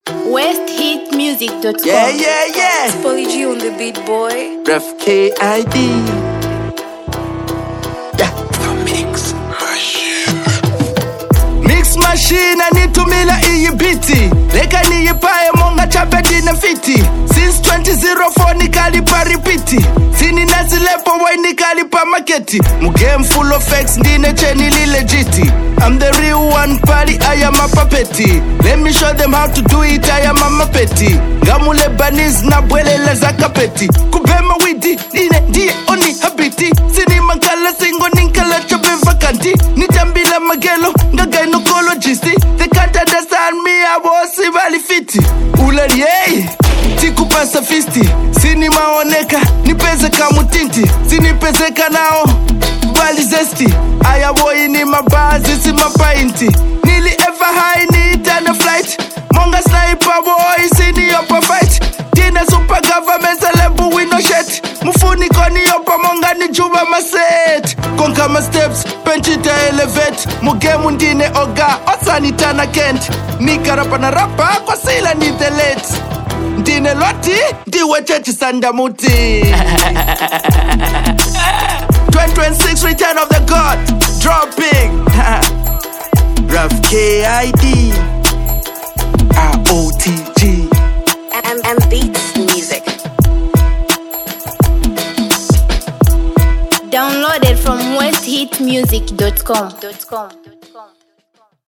Free StyleZambia Music
one of the country’s standout rap artists.